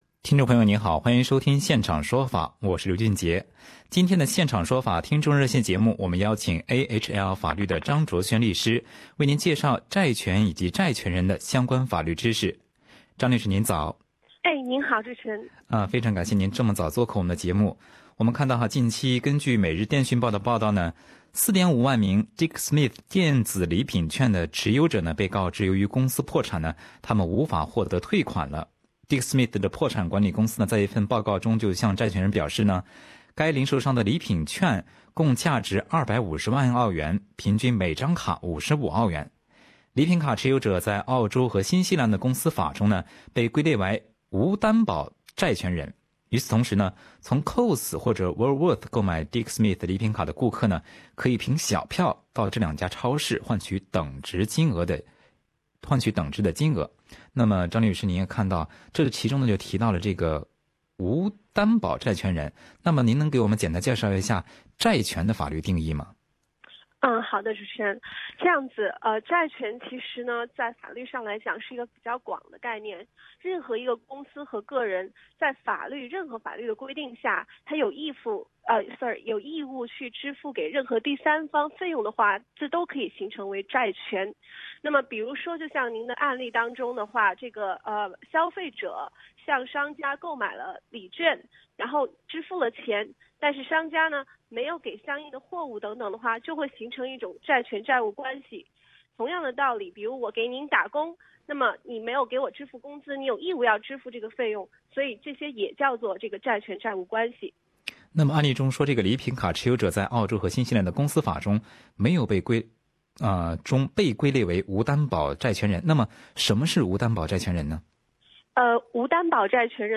本期《现场说法》听众热线节目